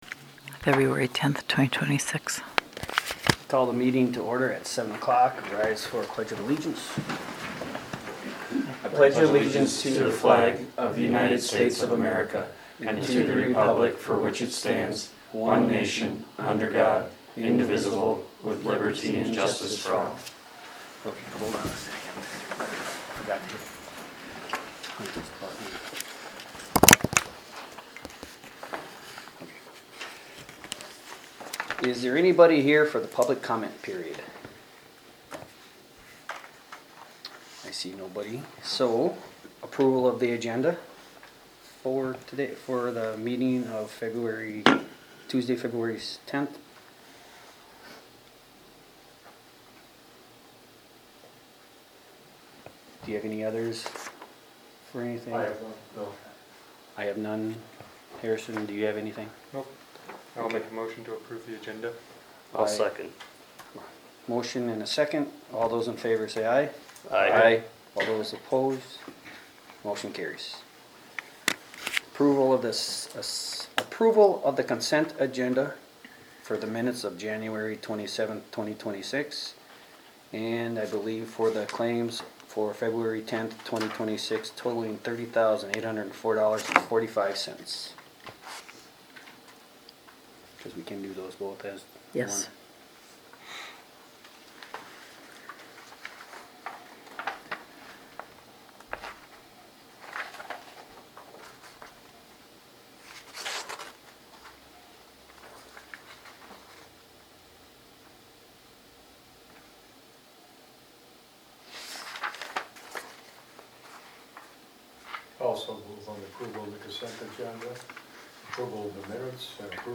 Meeting-2-10-26-Audio-Recording-GI-City-Council.mp3